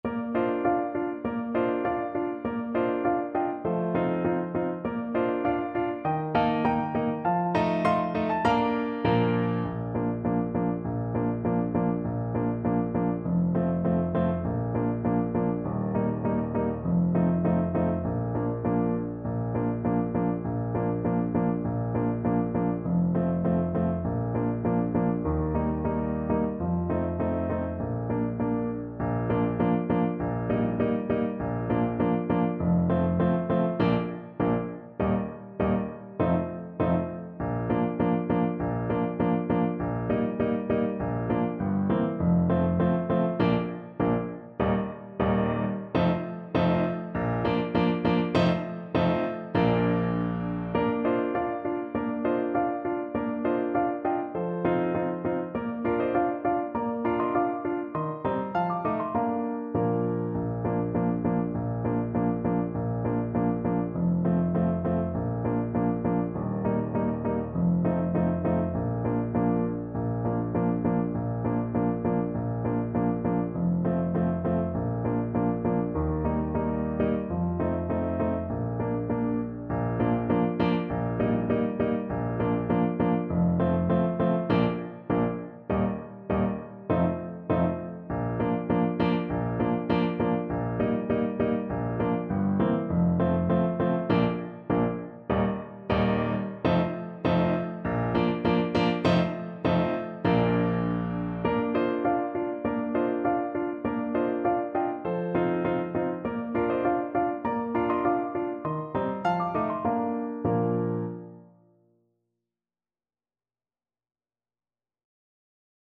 Play (or use space bar on your keyboard) Pause Music Playalong - Piano Accompaniment Playalong Band Accompaniment not yet available transpose reset tempo print settings full screen
Trombone
Bb major (Sounding Pitch) (View more Bb major Music for Trombone )
Moderato
4/4 (View more 4/4 Music)
Pop (View more Pop Trombone Music)